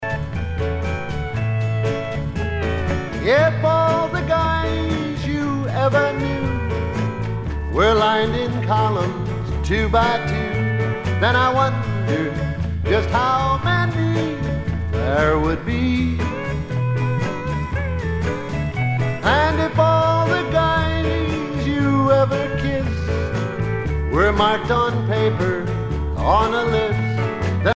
danse : slow fox
Pièce musicale éditée